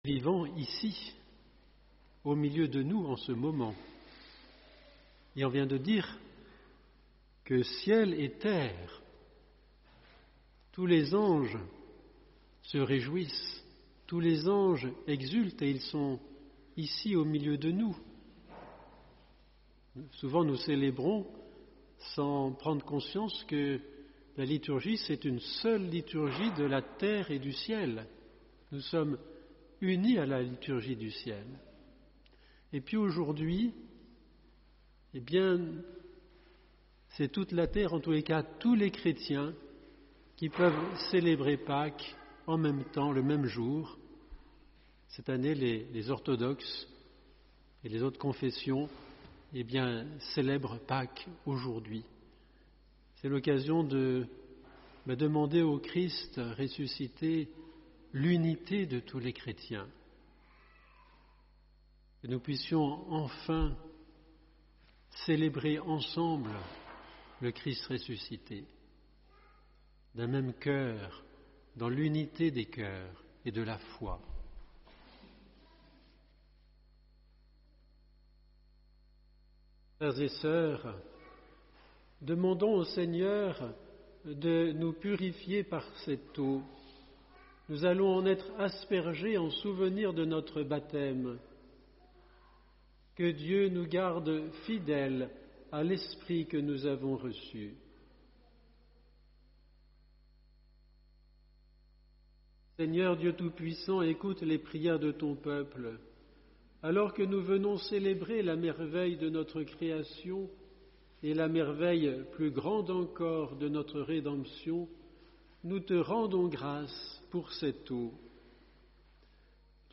Homélie Le matin du jour de Pâques, nous fait apparaitre un paradoxe étonnant : celui dont on célèbre la victoire avec tant d’éclat, on ne le voit pas.